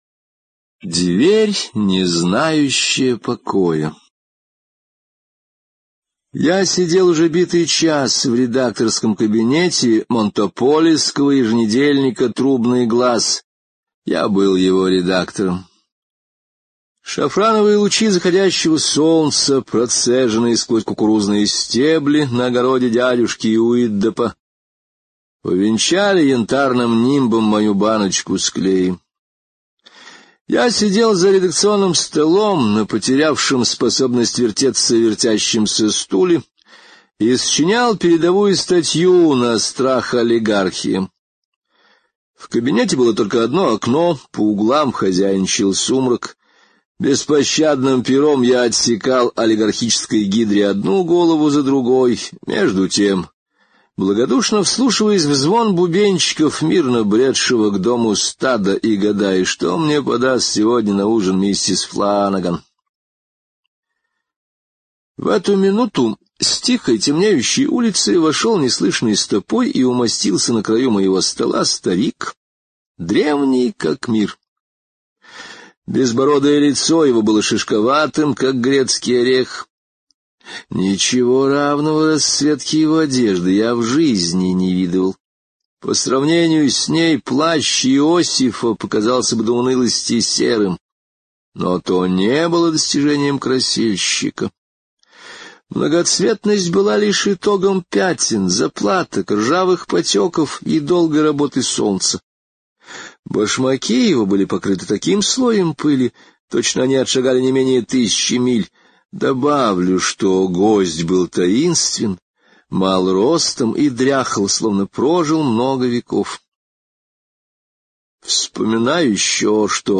Дверь, не знающая покоя — слушать аудиосказку Генри О бесплатно онлайн